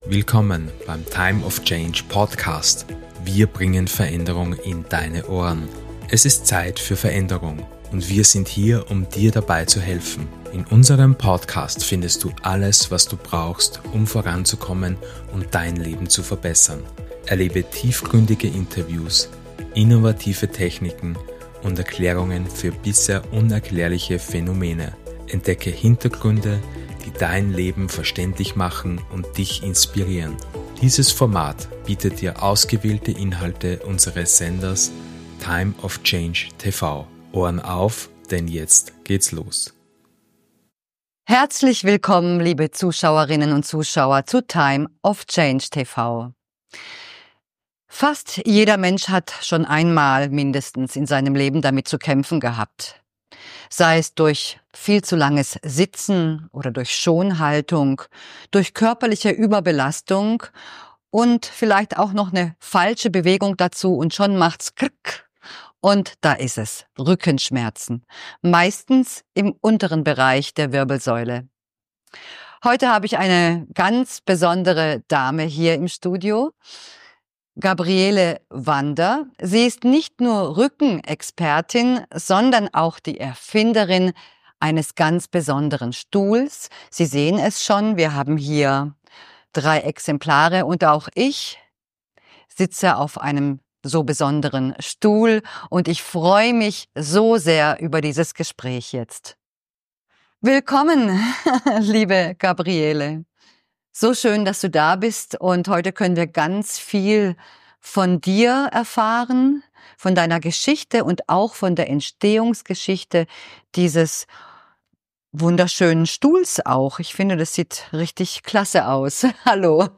Dieses Interview ist ein Muss für alle, die unter Rückenschmerzen leiden oder einfach mehr über gesundes Sitzen erfahren möchten.